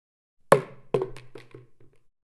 Звуки ведра
Упало пластиковое ведро